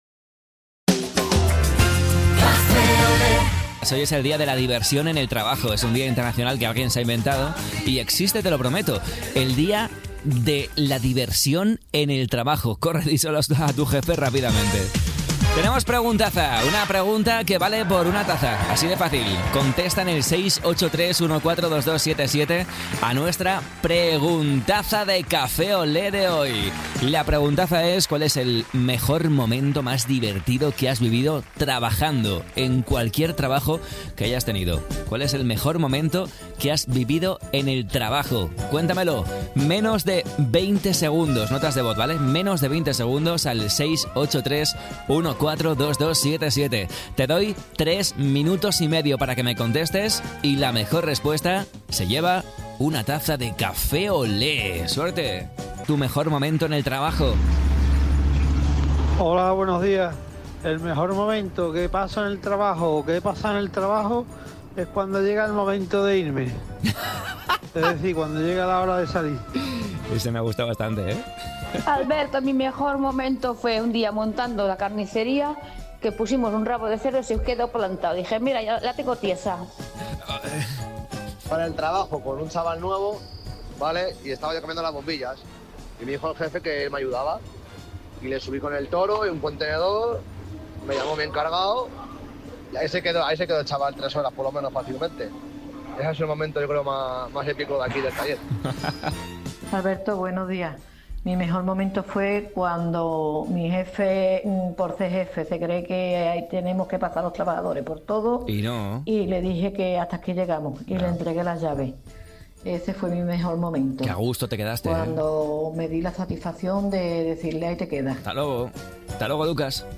El programa despertador de Radiolé